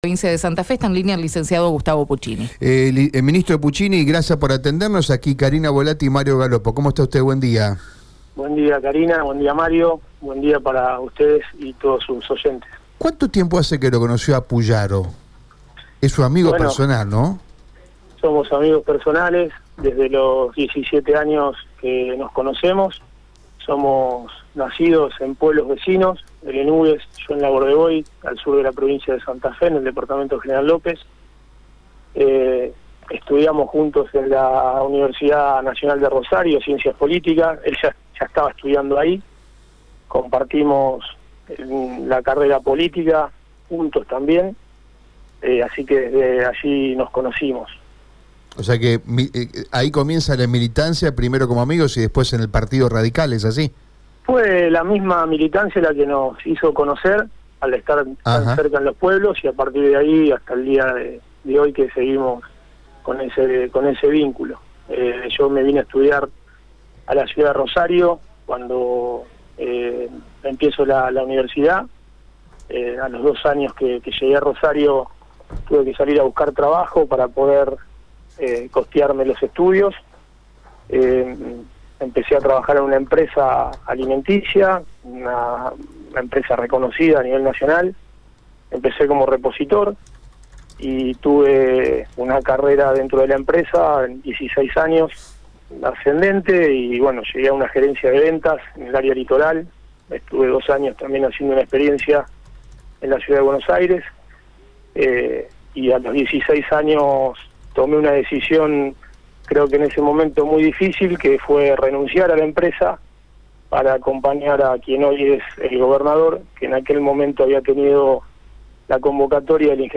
El ministro de Desarrollo Productivo de Santa Fe dialogó con Radio EME y se refirió a los principales temas que abordará su gestión.